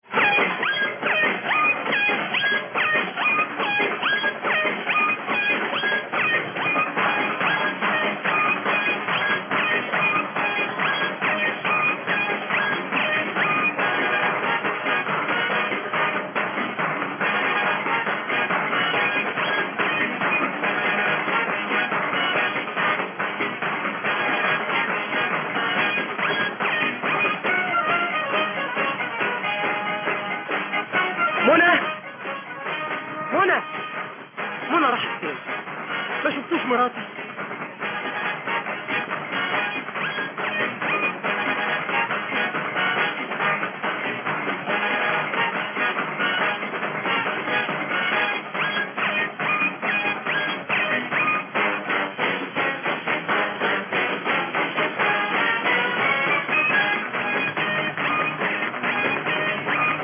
Please id the syntpop 70's Track